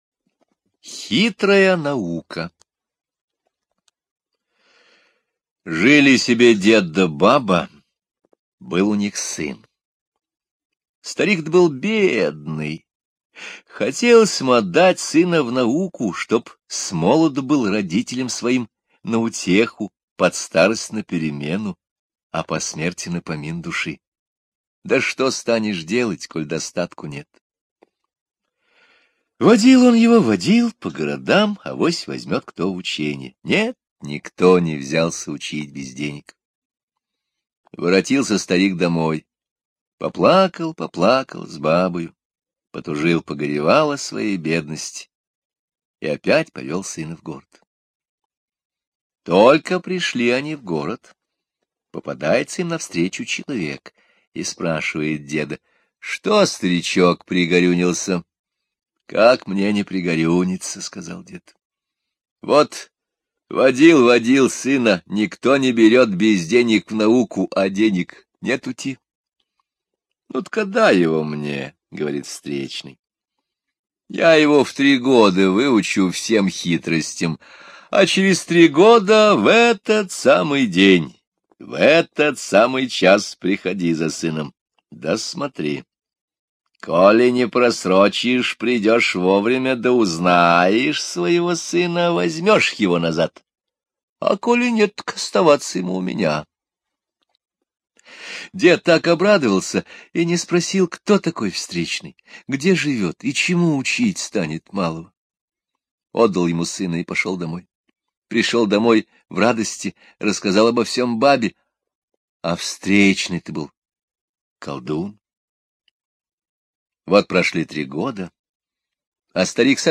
admin 19.04.2022 Русские народные аудиосказки Слушайте русскую народную сказку «Хитрая наука» на сайте Минисказка!